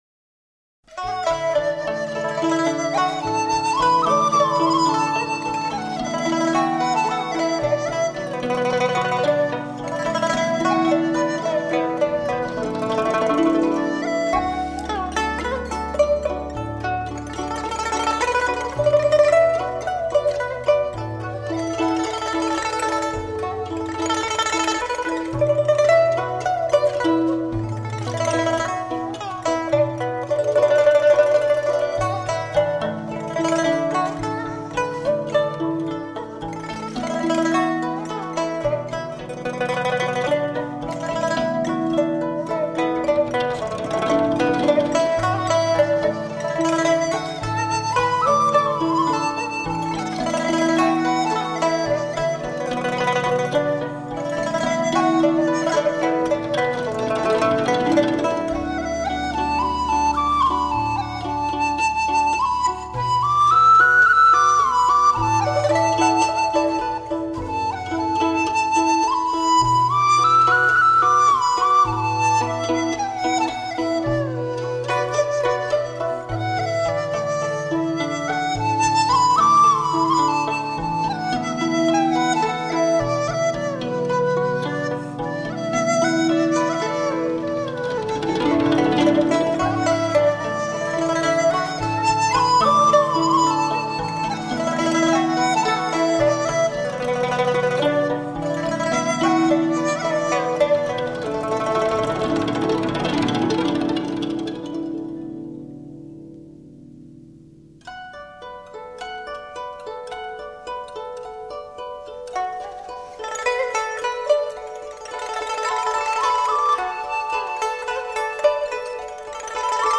温柔静谧 悠扬婉转 栩栩如生
民乐三重奏